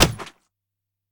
PixelPerfectionCE/assets/minecraft/sounds/item/shield/block3.ogg at ca8d4aeecf25d6a4cc299228cb4a1ef6ff41196e